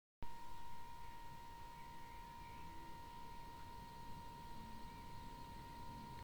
All-in-One - MSI CoreLiquid 240R V2 Geräusche (Summen, Fiepen)
Direkt nach dem Start ist mir ein unangenehmes, hohes Summen oder Fiepen aufgefallen.
Ich habe daraufhin alle Lüfter angehalten und musste feststellen dass die Geräusche von der Pumpe kommen. Klingt das nach Defekt oder kann ich da was machen?